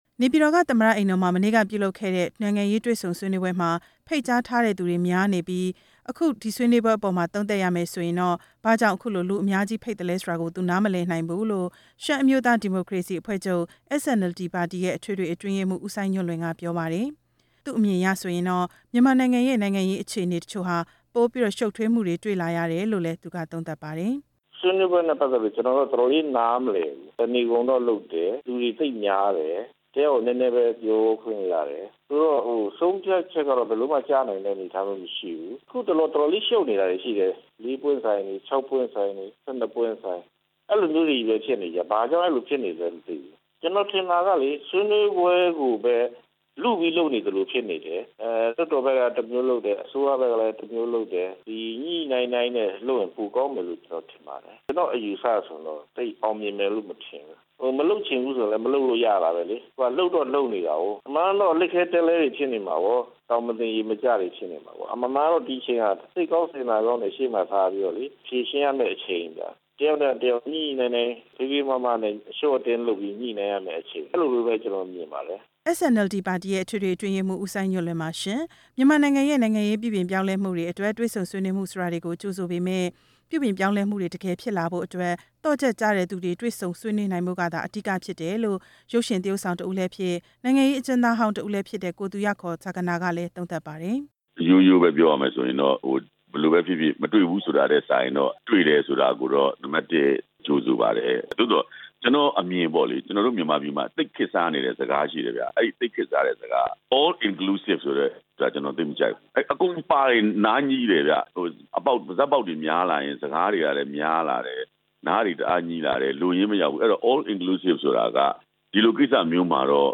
တင်ပြချက်